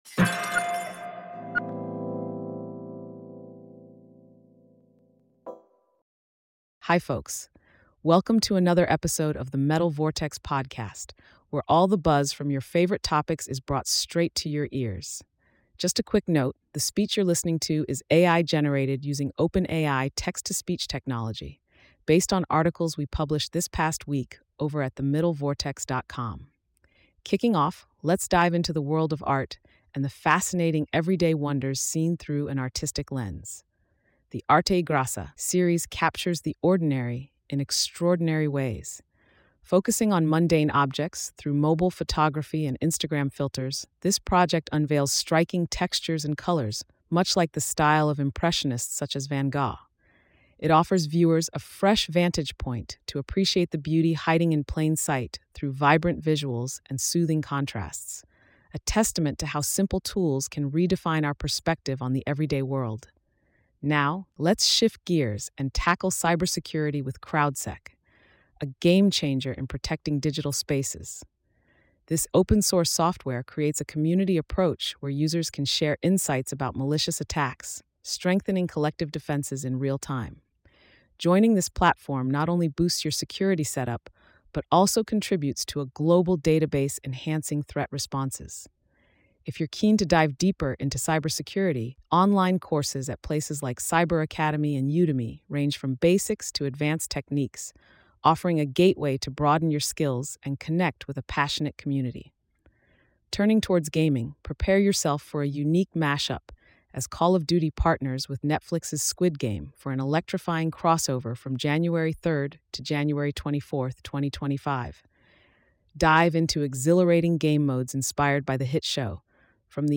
This week’s episode is packed with captivating topics and thought-provoking stories, all brought to life through AI-generated narration using OpenAI’s text-to-speech technology.